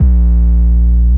808s
808 used in all songs but.wav